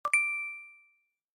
Apple Pay Sound Button: Meme Soundboard Unblocked